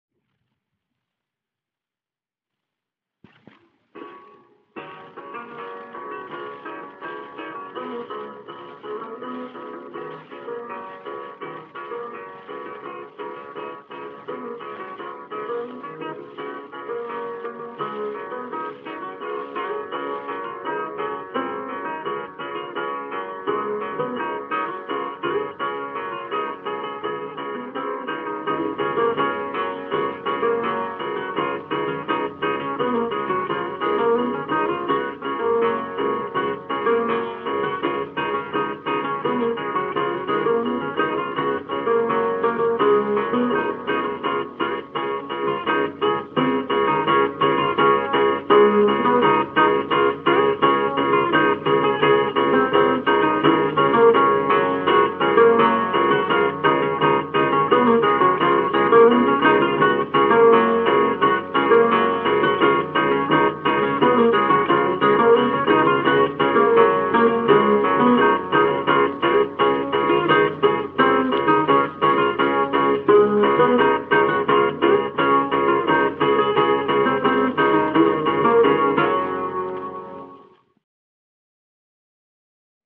Chótis